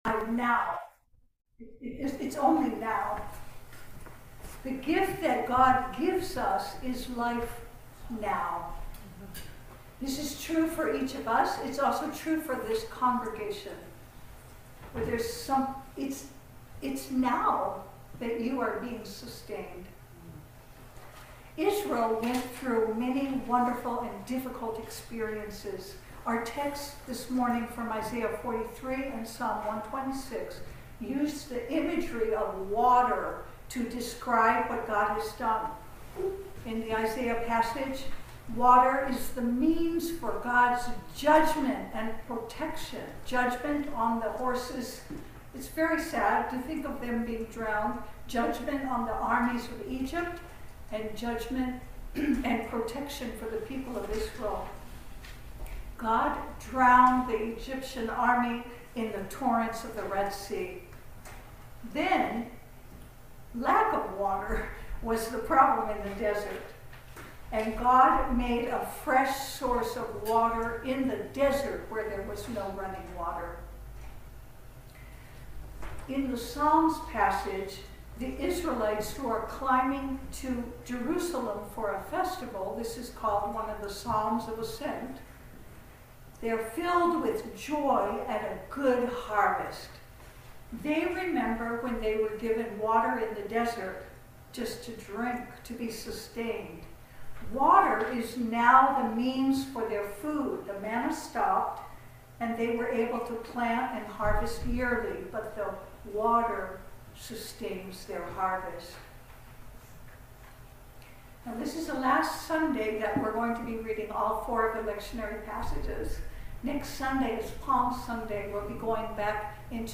Philippians 3:4b-14 Service Type: Sunday Service We go forward into each morning.